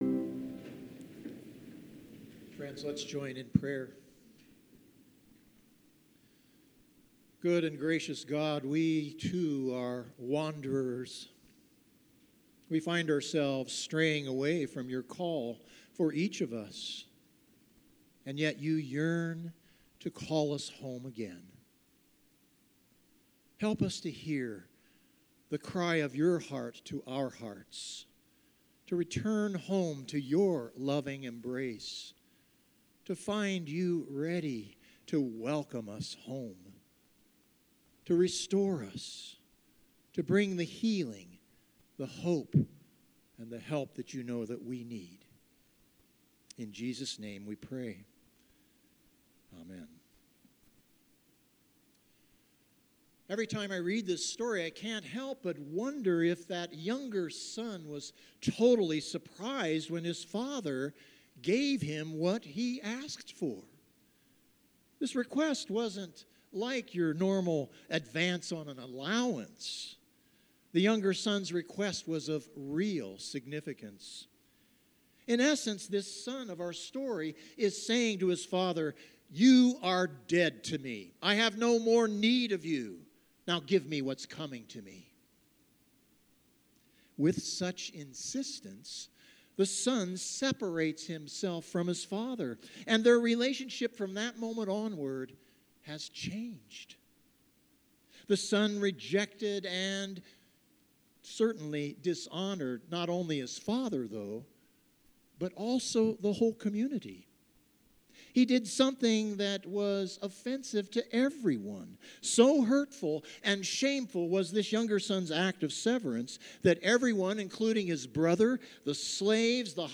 Sermons | Moe Lutheran Church